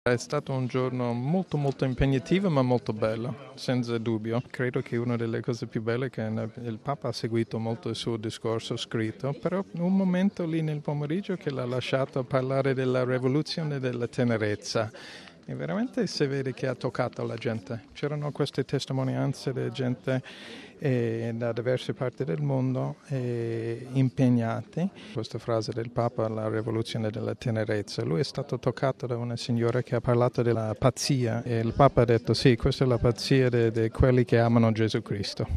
Su questa storica giornata di Papa Francesco con i luterani, ascoltiamo il direttore della Sala Stampa vaticana Greg Burke